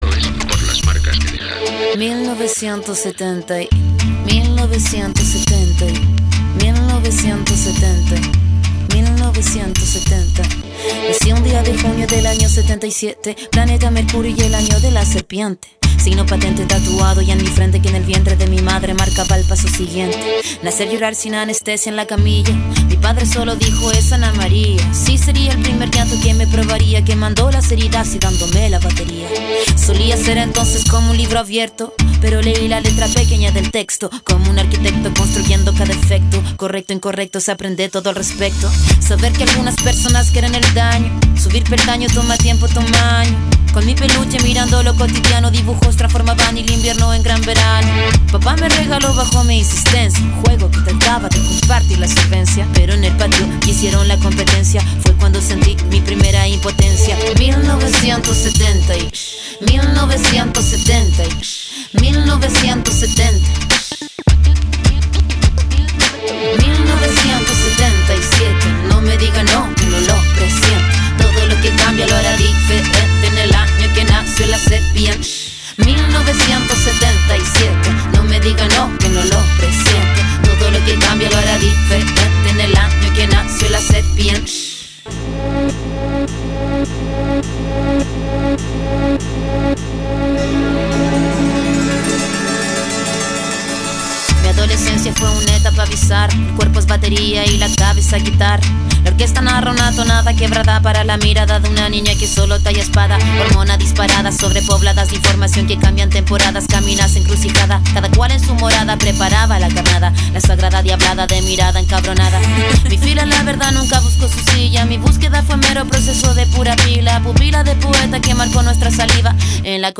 • Качество: 192, Stereo
Хип-хоп
женский голос